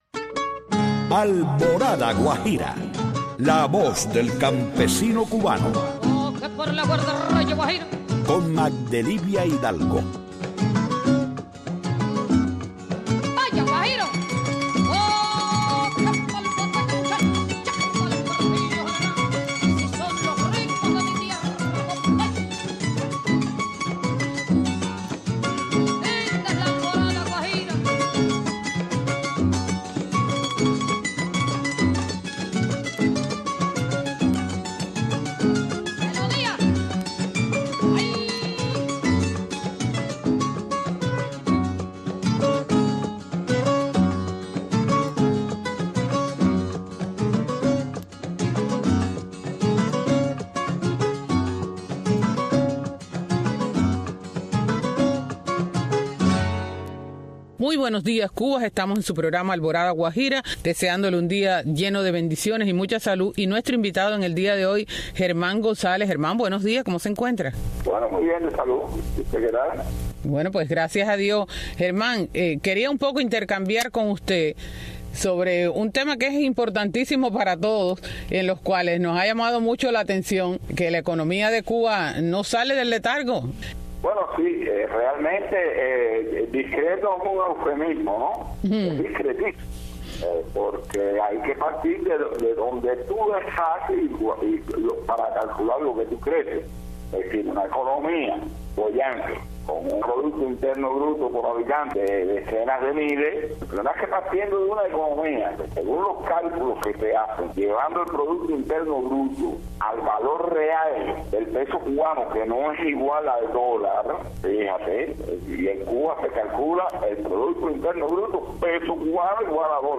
Una hora con temas para el campesino, entrevistas y música.